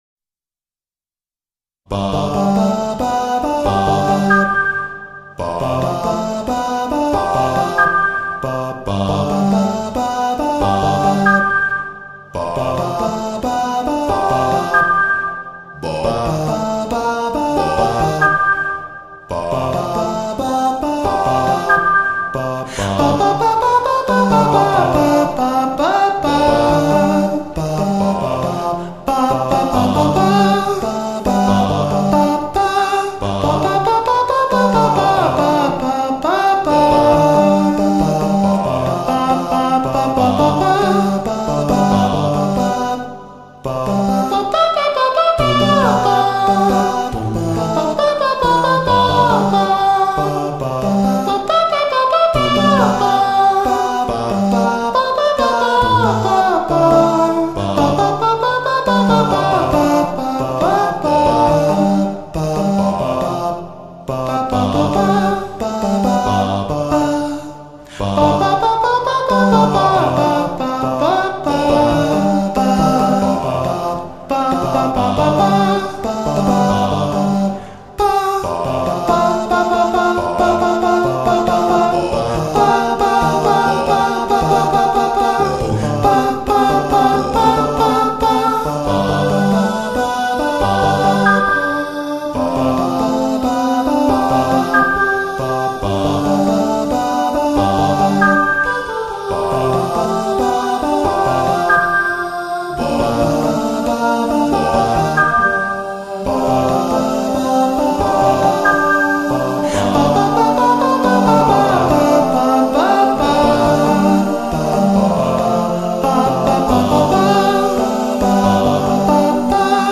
Acapella Version